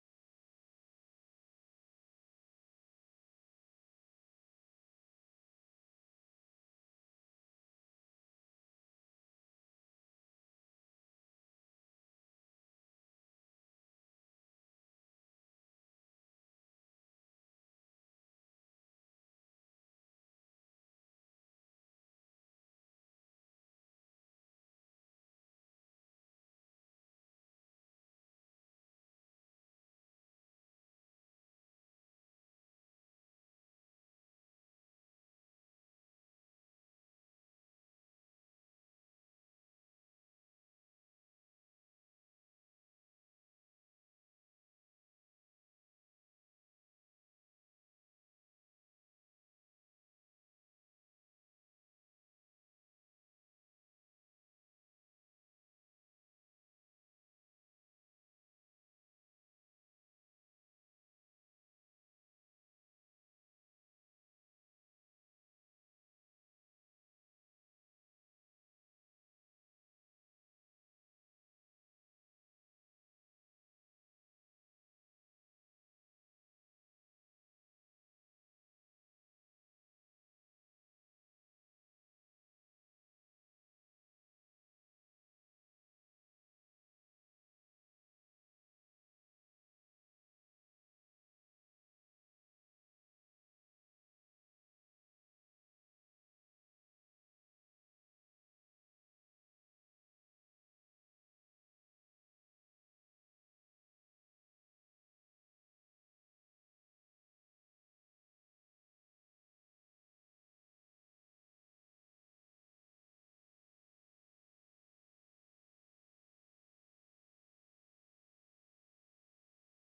Service Type: Sunday Morning Sorry, there is a problem with this audio file.